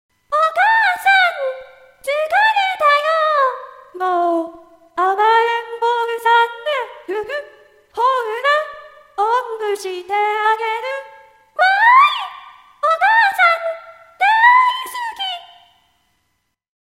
ヤマハ歌声合成ソフト『VOCALOID』Part4
キーがちょっと高すぎない？